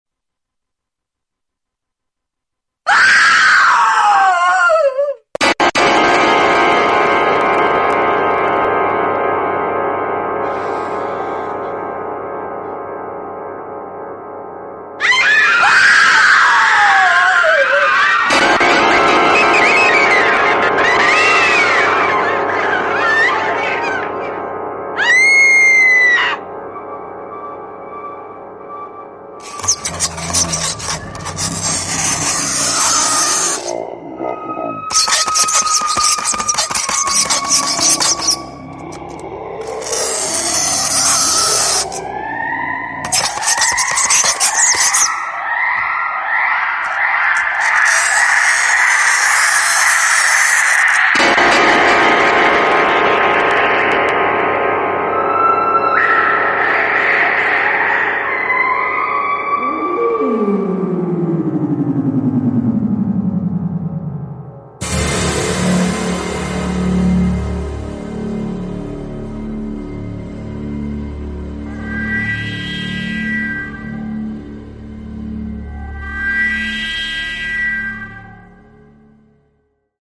Звуки страха, криков
Крики женщины и пугающие звуки